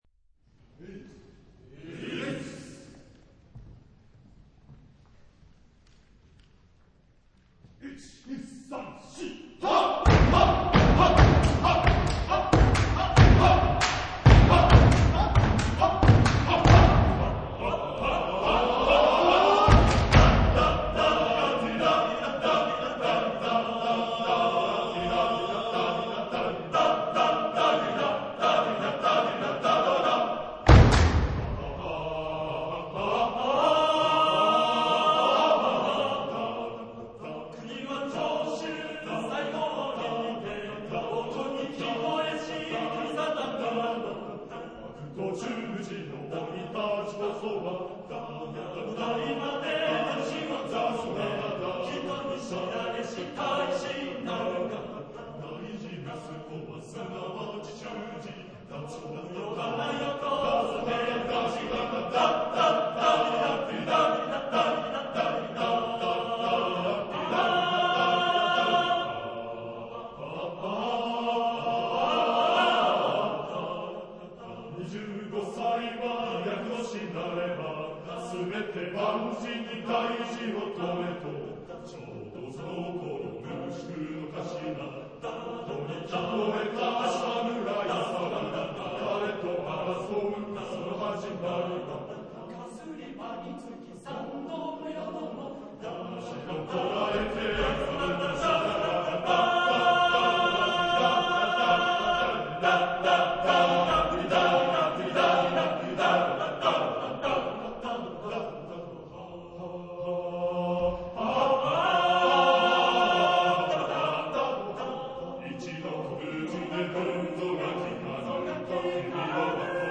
Género/Estilo/Forma: Folklore ; Canción descriptiva
Tipo de formación coral:  (4 voces iguales )